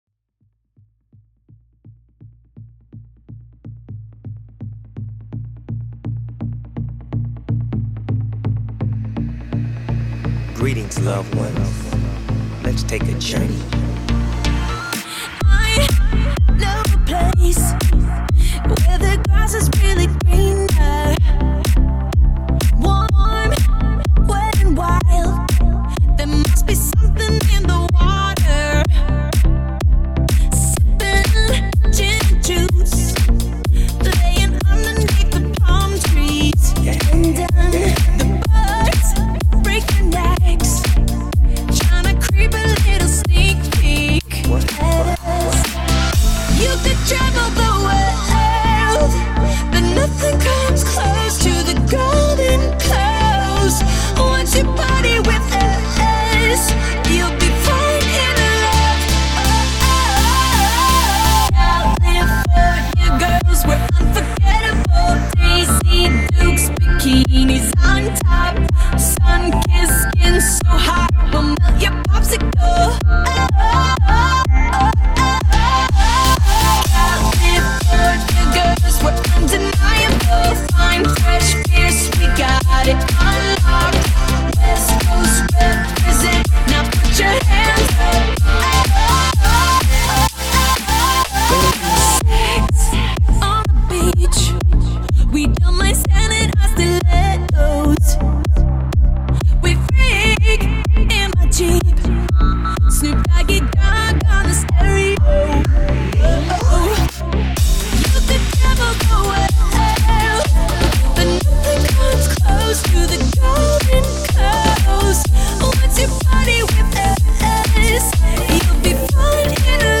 progressive house progressive trance